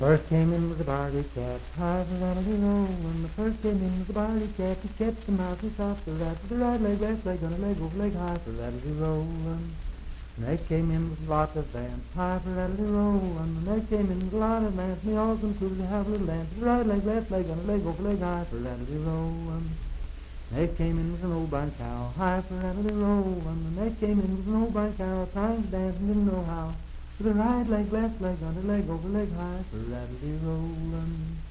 Unaccompanied vocal music
Dance, Game, and Party Songs
Voice (sung)
Pocahontas County (W. Va.), Marlinton (W. Va.)